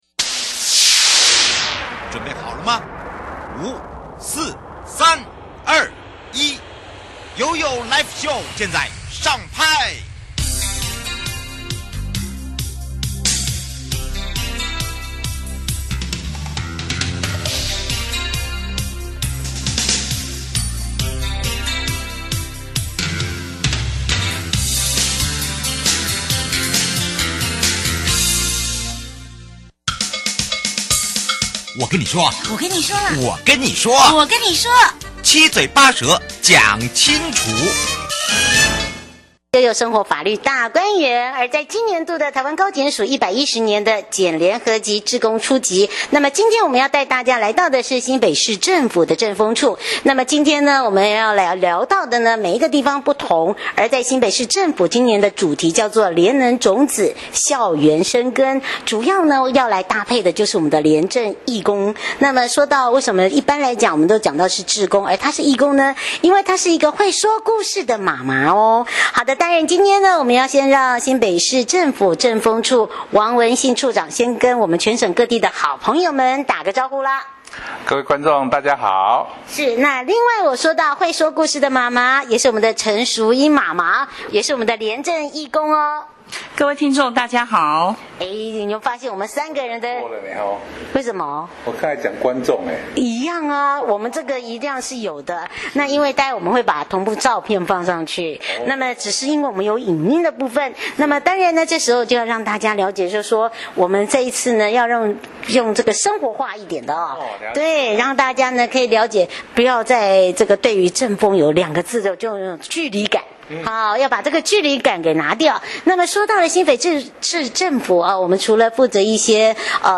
專訪主題：廉能種子．校園深耕